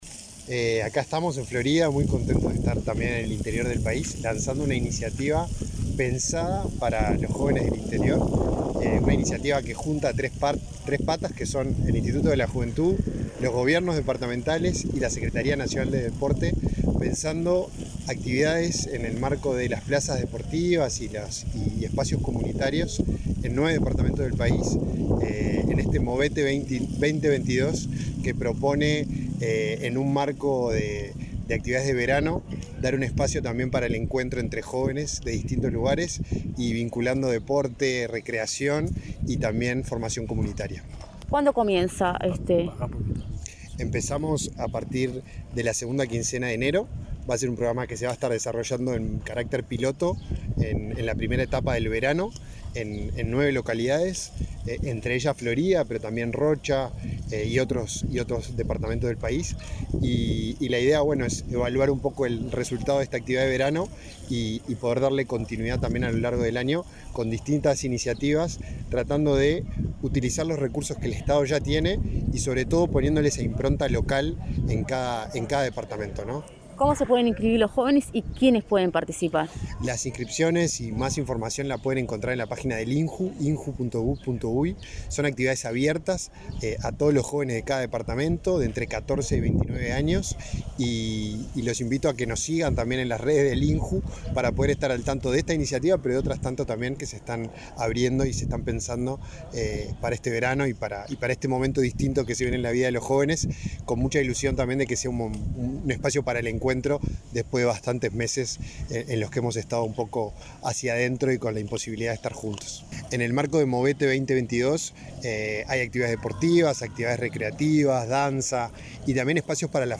Entrevista al director del INJU, Felipe Paullier
El director del Instituto Nacional de la Juventud (INJU), Felipe Paullier, dialogó con Comunicación Presidencial sobre la propuesta Movete 2022, un